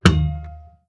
Efecto de golpeo a una cuerda de una guitarra